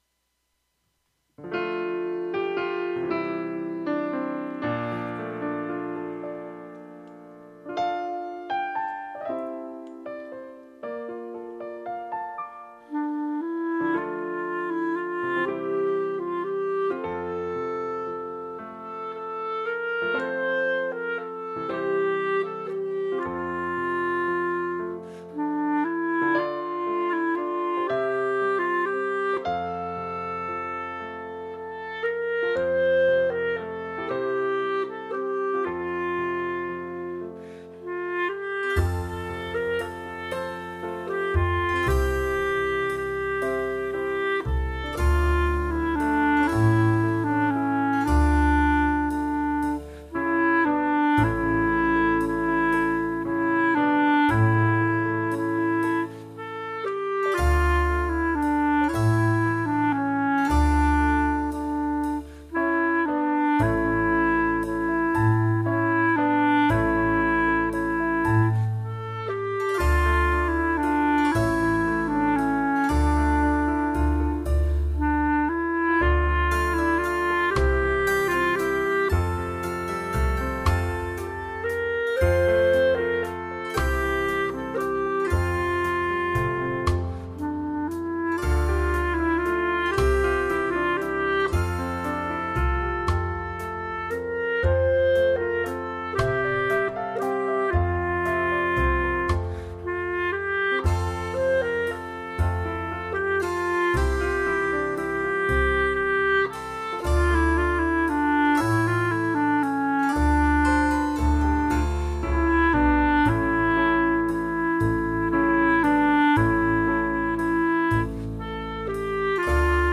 久しぶりのカラオケ録音アップロードします。
最初の３曲は以前にもアップロードしてありましたが、楽器をエリートに替えて、再度録音しました。マウスピースは、ヴァンドレン11.1です。
後半２曲はクラシックのアレンジものです。